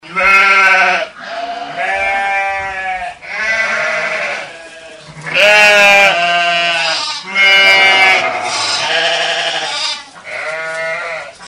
Звуки блеяния баранов и овец